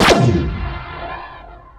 v19_shoot.wav